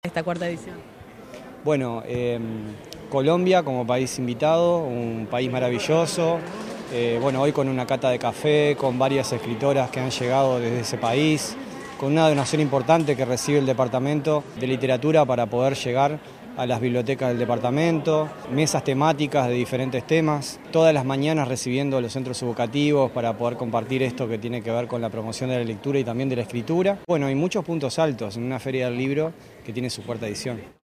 Por su parte, el Director General de Cultura de Canelones, Sergio Machín, enfatizó que la FILC representa una celebración de la cultura al promover la lectura, la escritura y diversos aspectos artísticos presentados a lo largo de la semana.